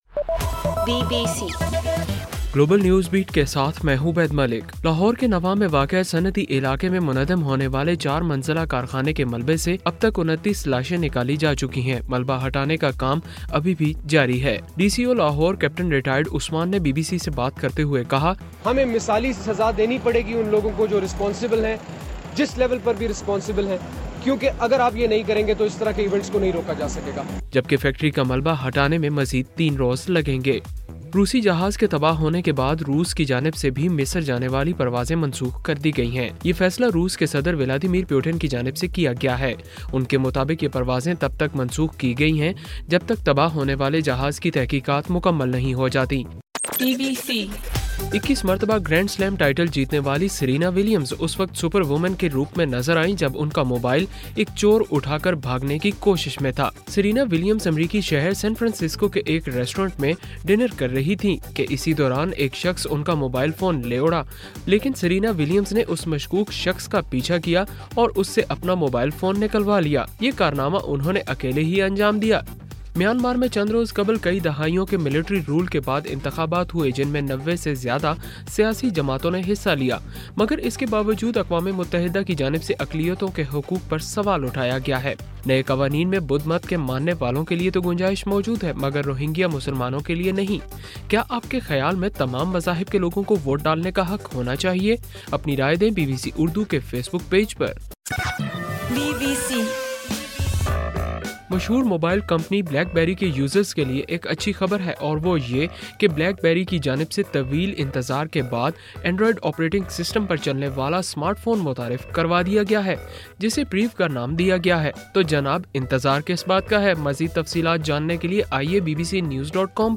نومبر 6: رات 12 بجے کا گلوبل نیوز بیٹ بُلیٹن